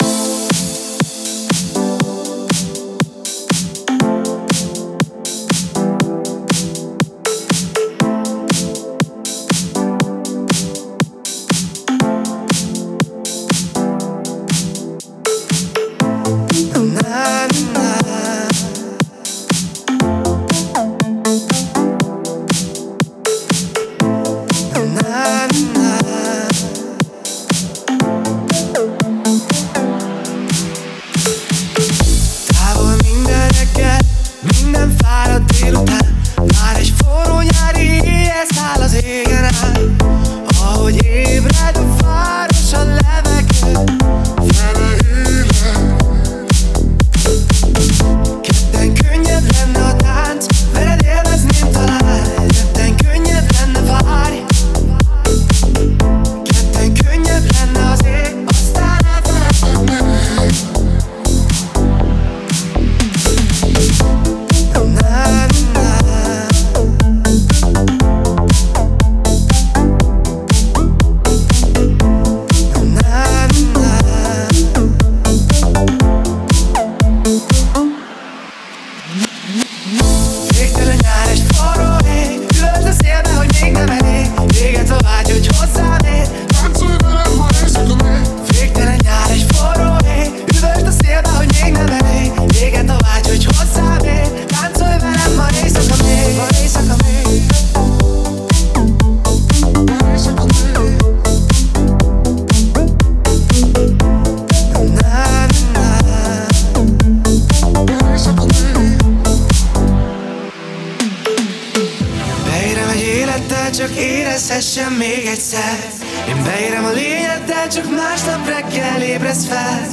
это зажигательная танцевальная композиция в жанре EDM